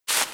SandStep3.wav